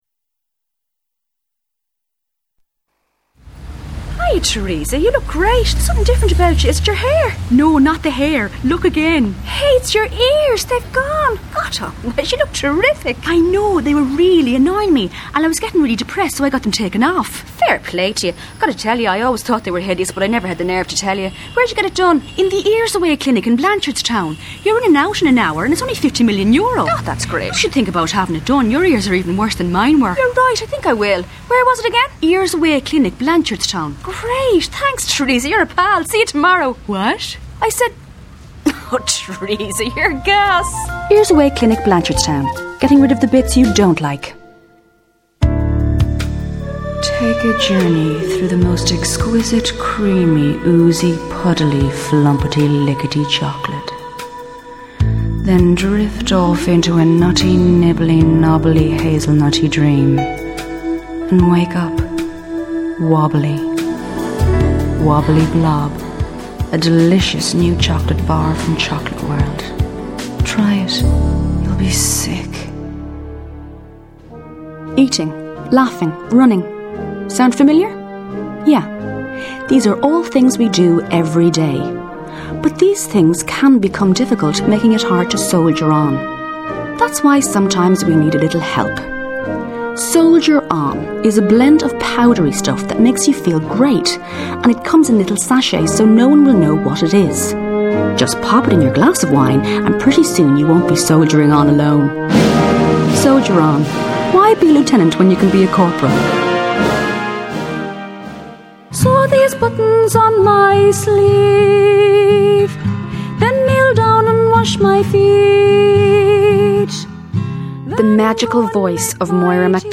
voice sampler